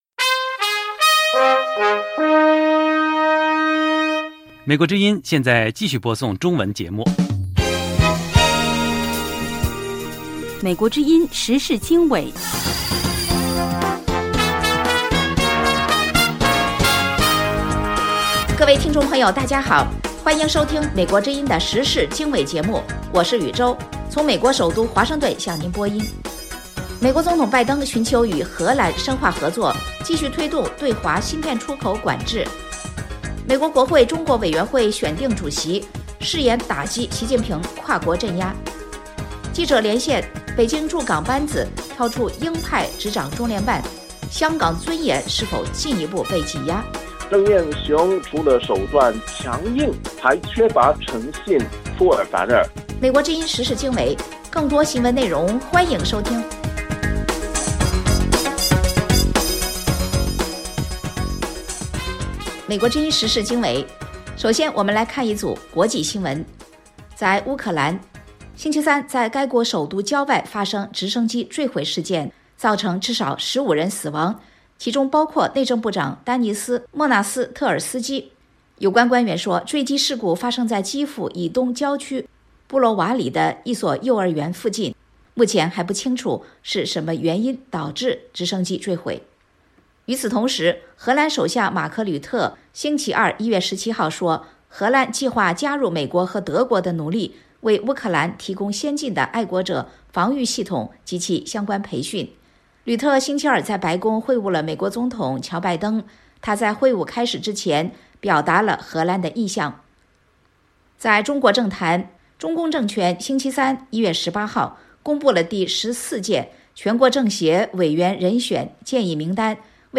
2/美国国会中国委员会选定主席，誓言打击习近平跨国镇压。3/记者连线：北京驻港班子挑出鹰派执掌中联办，香港尊严是否进一步被挤压？